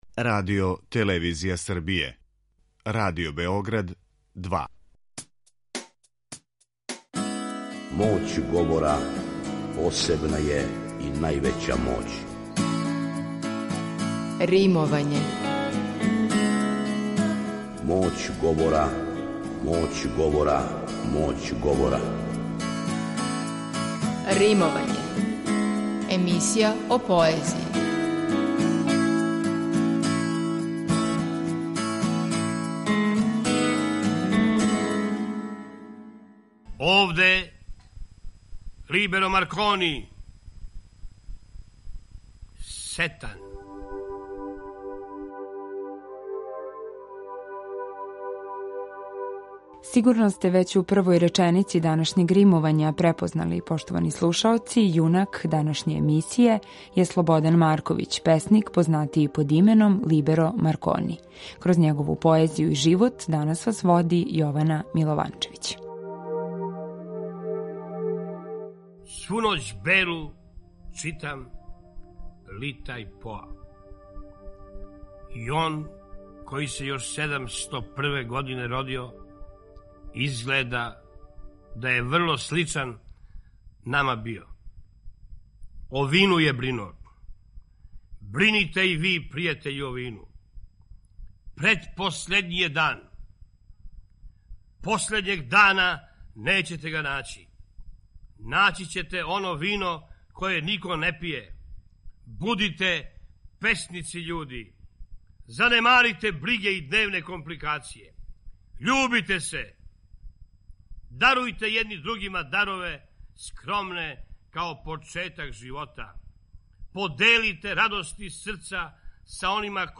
Бићемо у прилици да чујемо неке од најпознатијих стихова у његовој интерпретацији. О Либеру Марконију ће говорити и песникови пријатељи и колеге.